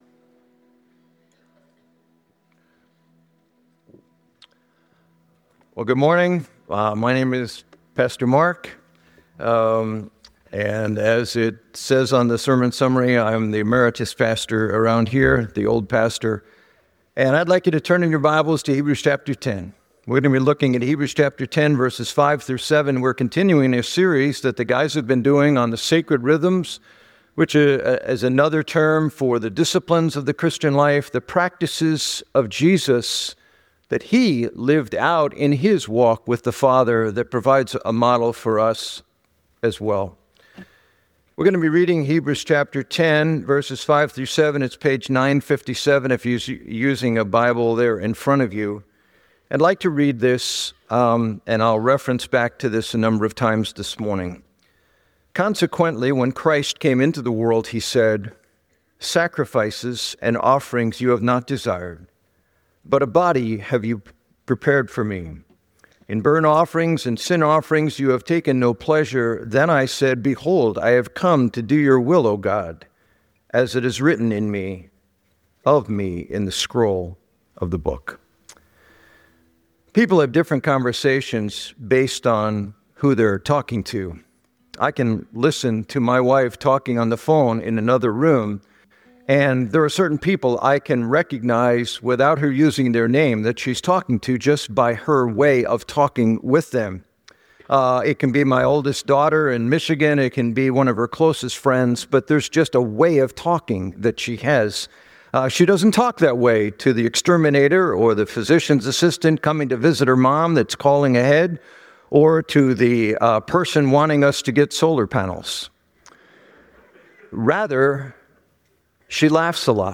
preaches on the importance of prayer as a conversation with God